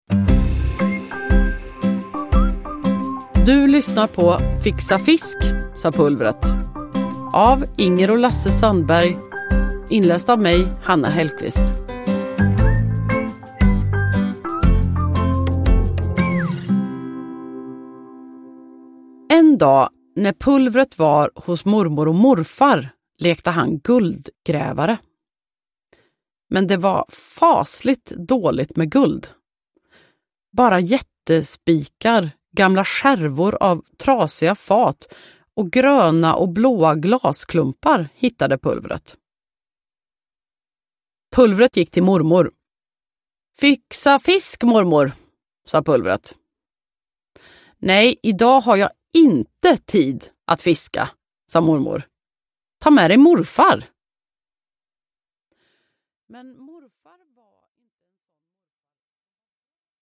Fixa fisk, sa Pulvret – Ljudbok – Laddas ner
Uppläsare: Hanna Hellquist